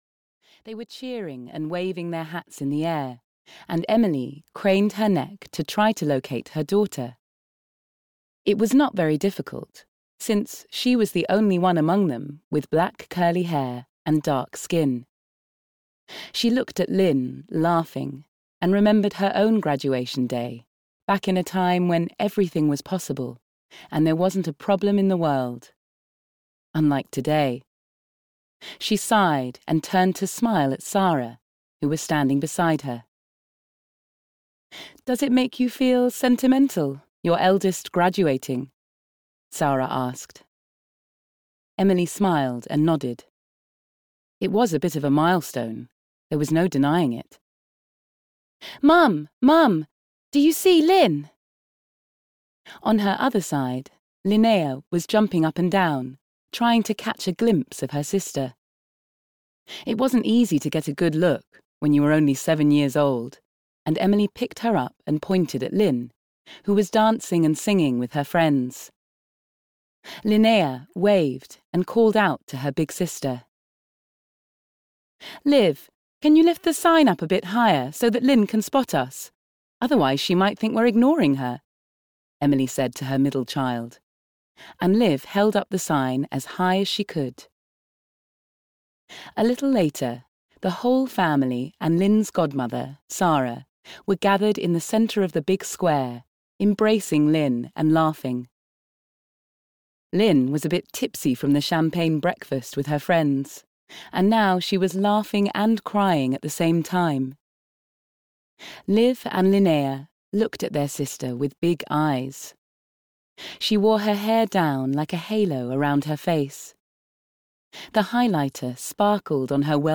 Home for Christmas (EN) audiokniha
Ukázka z knihy